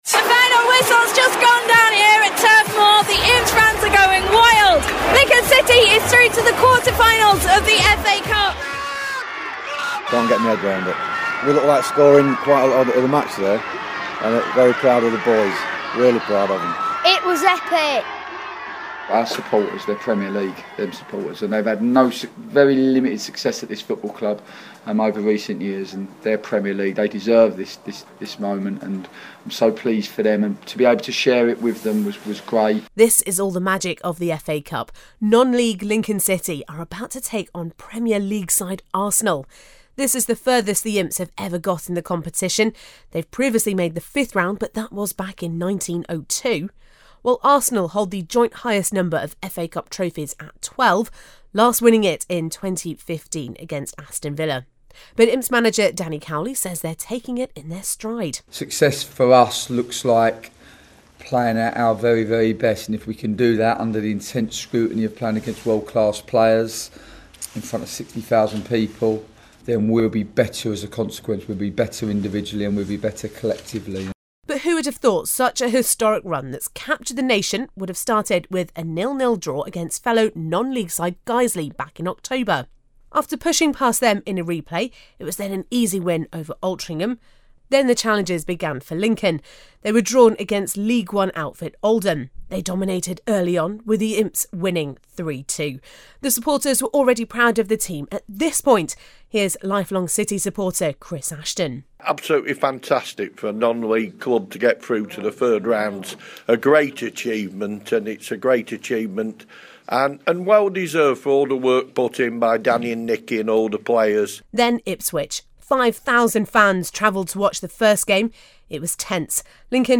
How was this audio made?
They quickly made an impression and soon enough they were facing Arsenal in the quarter finals of the FA Cup. This documentary, which went out on Lincs FM in March 2017, follows the story of the competition.